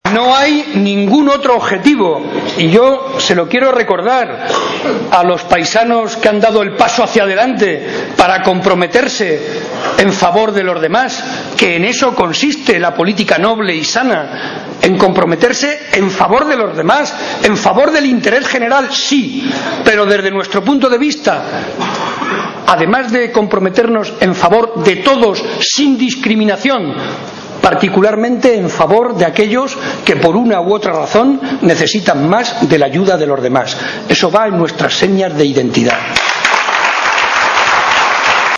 Momento del acto celebrado en Campo de Criptana.